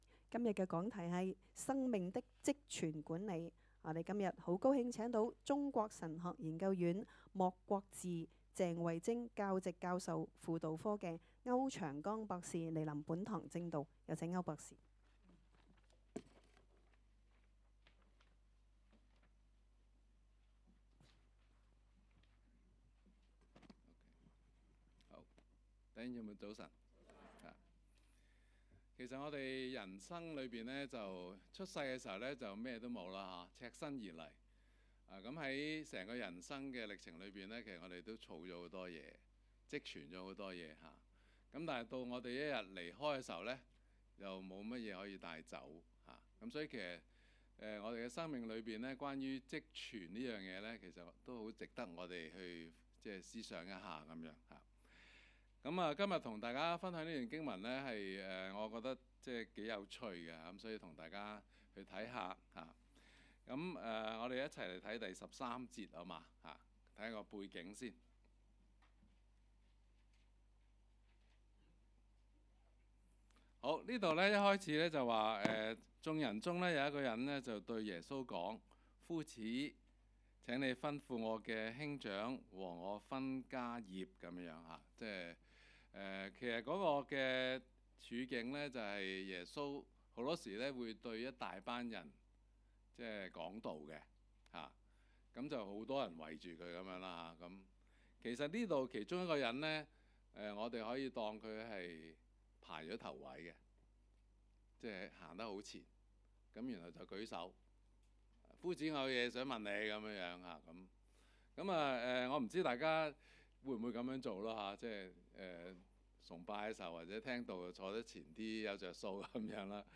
崇拜講道